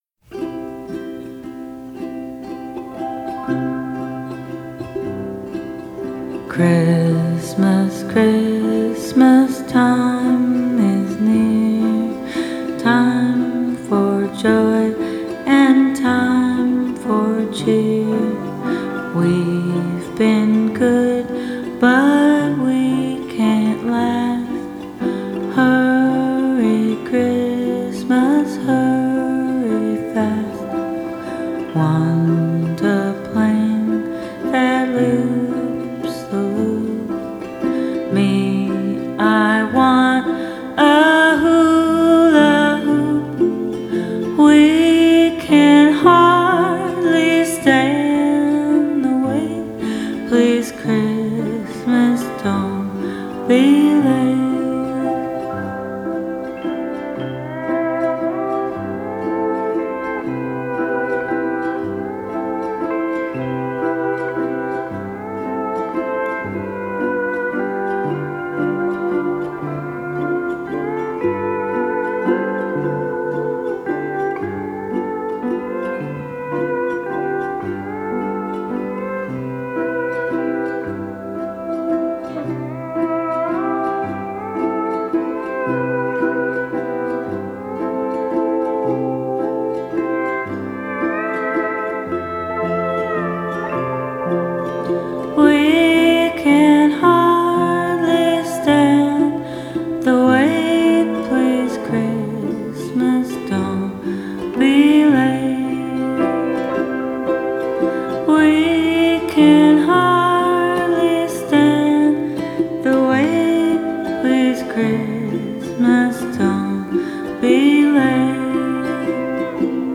It’s a waltz.